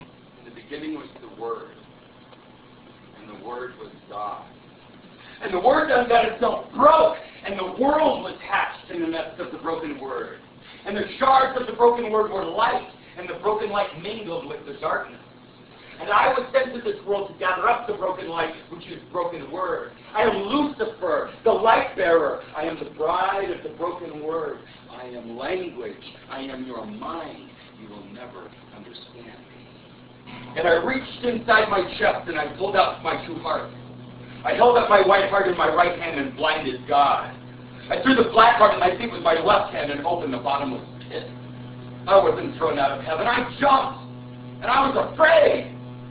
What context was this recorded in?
performed by him in Denver in 1996.